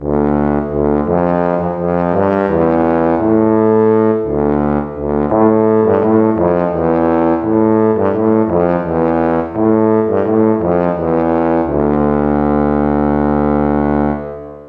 Tuba. Breve pieza.
aerófono
viento
grave
metal
Instrumentos musicales